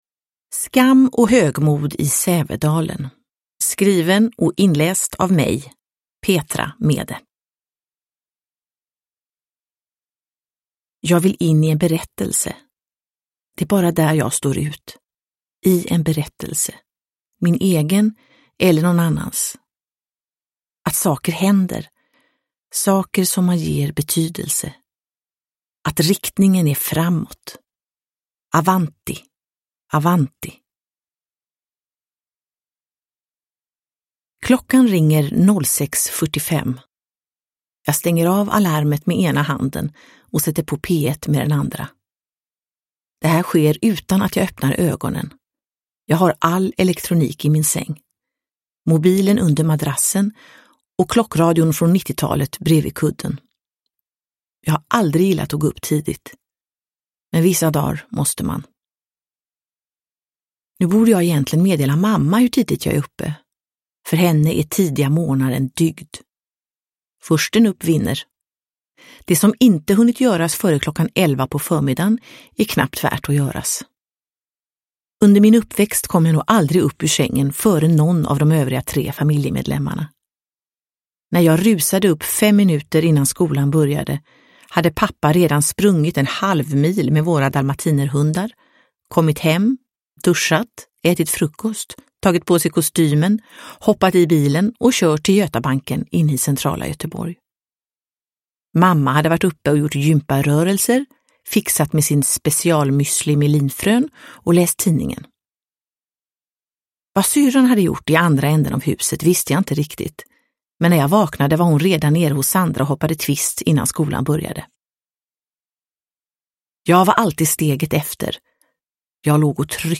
Skam och högmod i Sävedalen – Ljudbok – Laddas ner
Uppläsare: Petra Mede